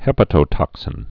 (hĕpə-tō-tŏksĭn, hĭ-pătō-)